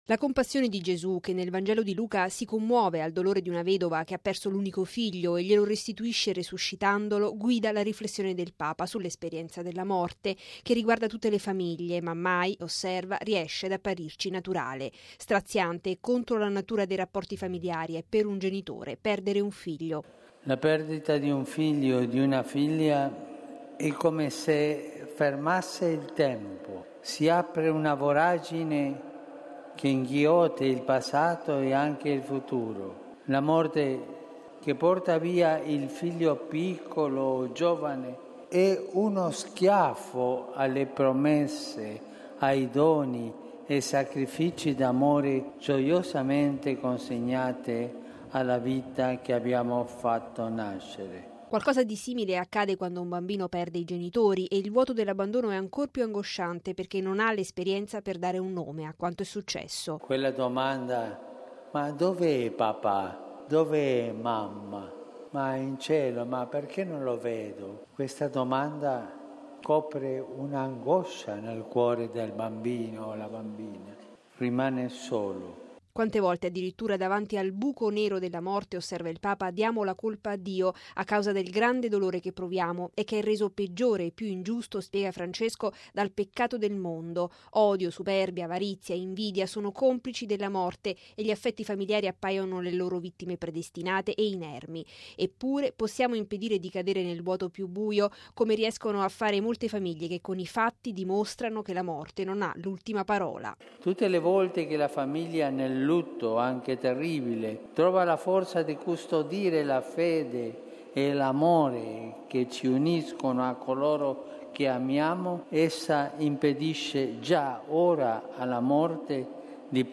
Sono la fede e la forza dell’amore a impedire che la morte renda vani i nostri affetti e ci faccia cadere nel vuoto più buio. Questo il cuore della catechesi che davanti a 25mila fedeli in Piazza San Pietro, il Papa oggi ha dedicato ancora alla vita della famiglia, in particolare all’esperienza straziante del lutto.